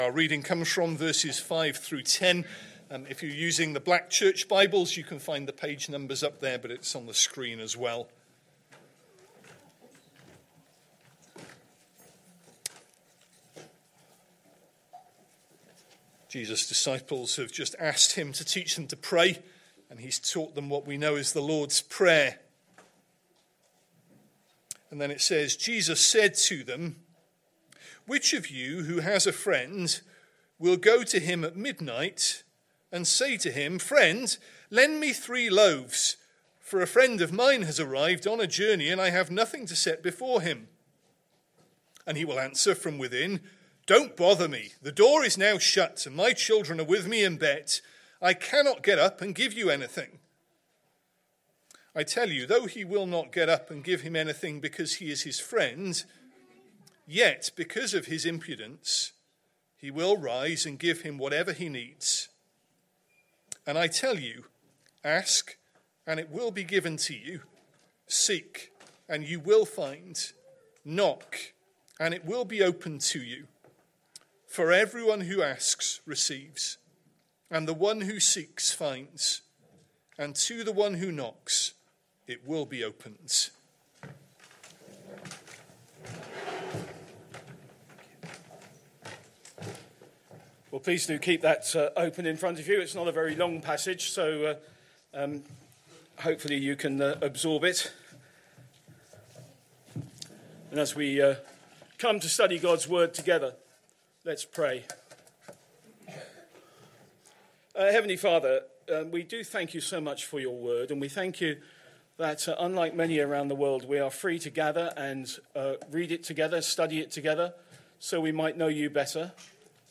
Sunday Morning Service Sunday 3rd August 2025 Speaker